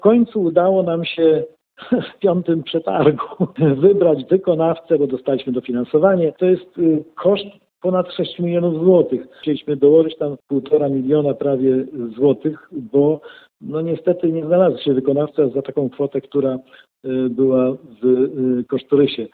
– Nie było łatwo ruszyć z tą inwestycją – mówi starosta powiatu ełckiego Marek Chojnowski.